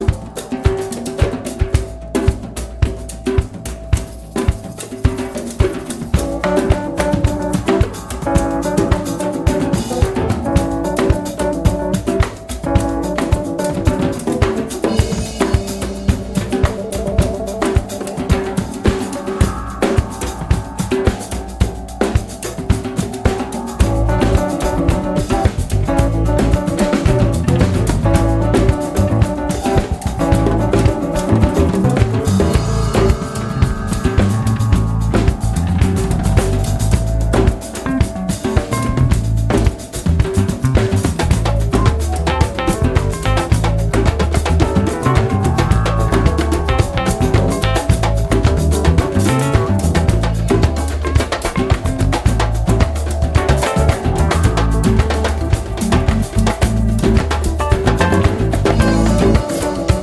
• Sachgebiet: Praise & Worship